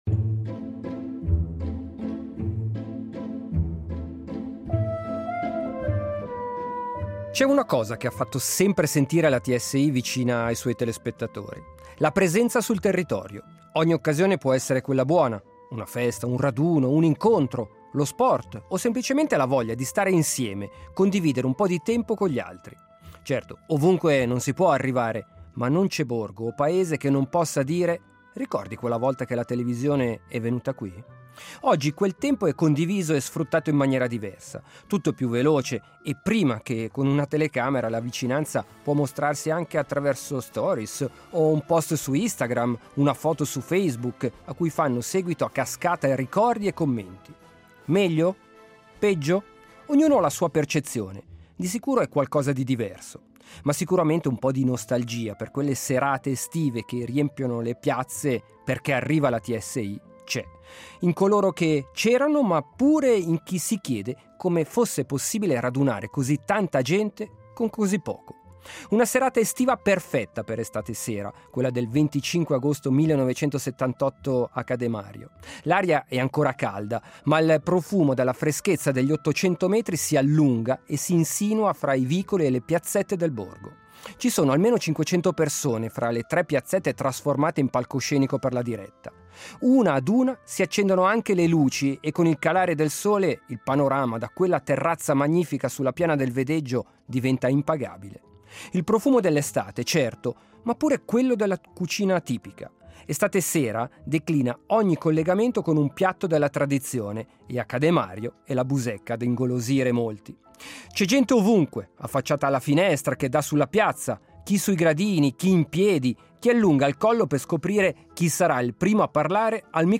una puntata interamente in dialetto